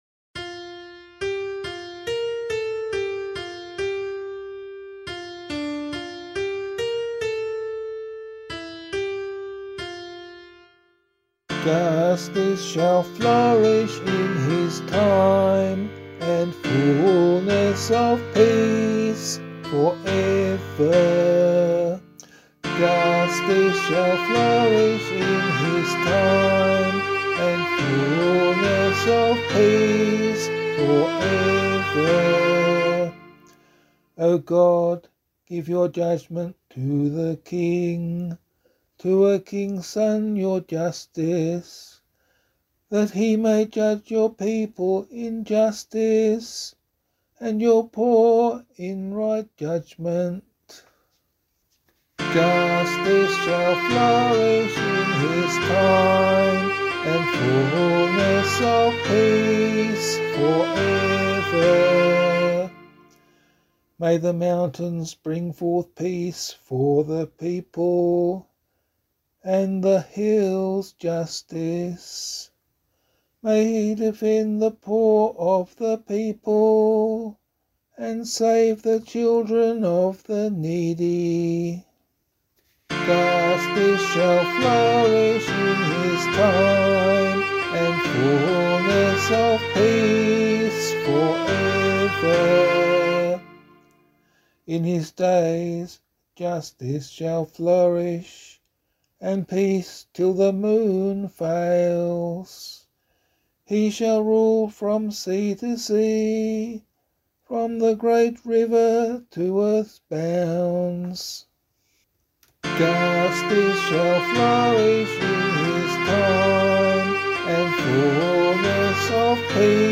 215 ANZAC Day Psalm B [LiturgyShare 1 - Oz] - vocal.mp3